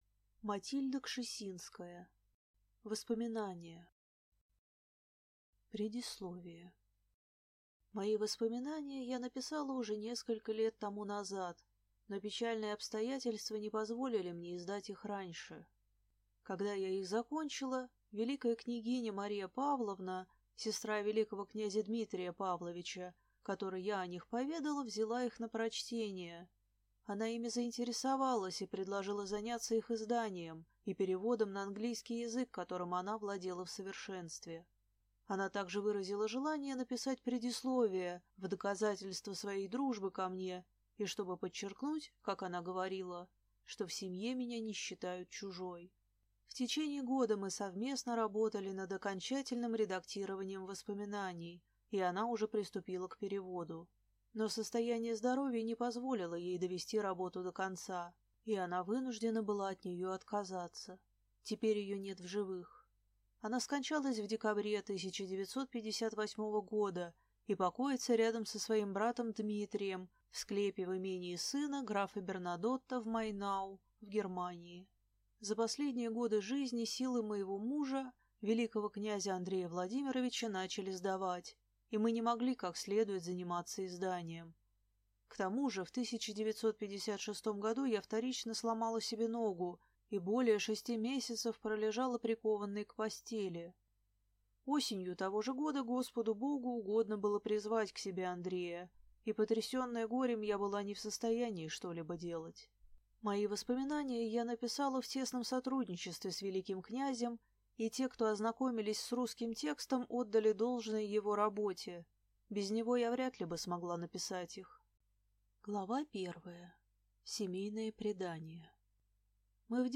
Аудиокнига Воспоминания | Библиотека аудиокниг